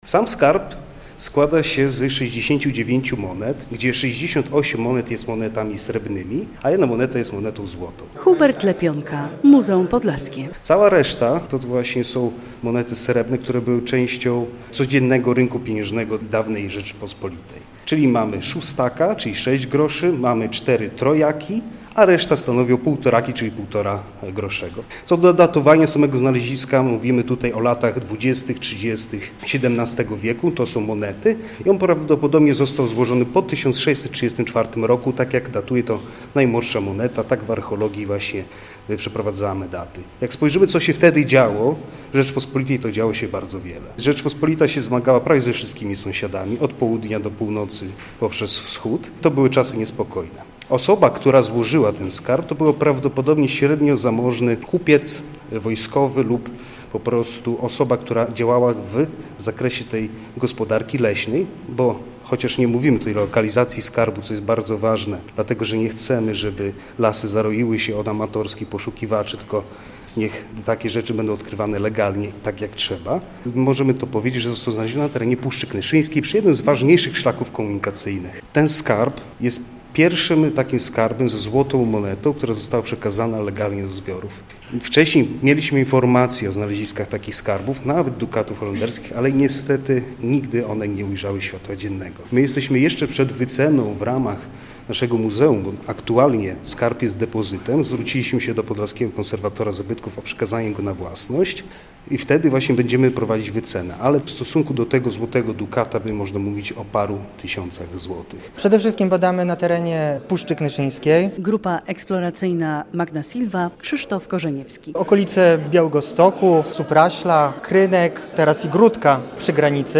Skarb z Puszczy Knyszyńskiej - relacja